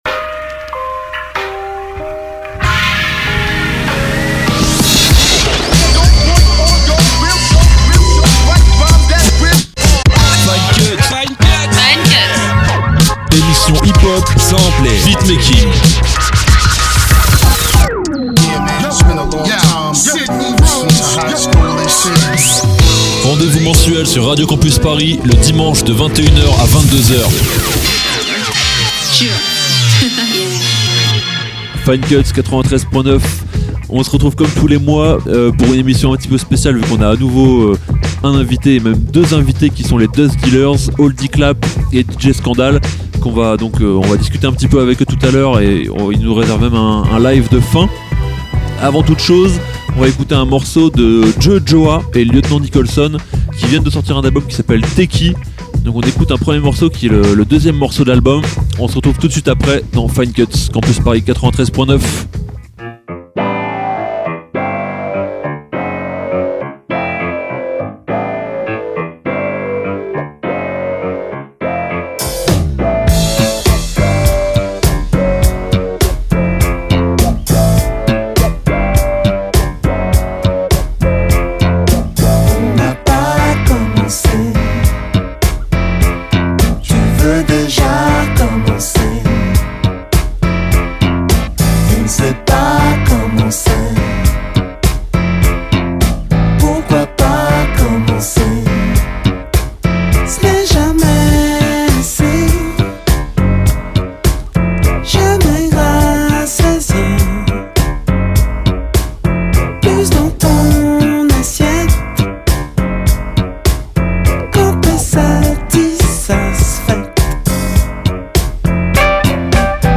L’occasion d’écouter quelques titres ensemble, de tailler le bout d’gras et de les inviter à une session live derrière les platines et la MPC.
Hip-hop